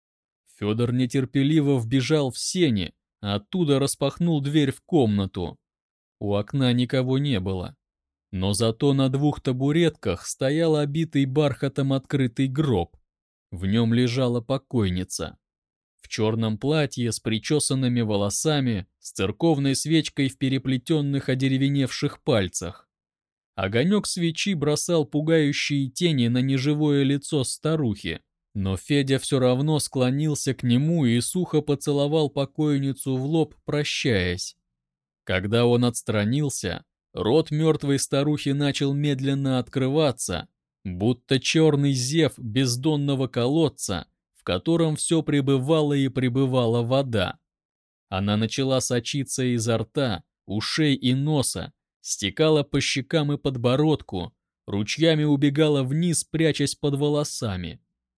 Муж, Аудиокнига/Средний
Focusrite 2i2 2nd gen., Audio-Technica AT2035